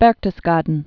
(bĕrktəs-gädn, bĕr-)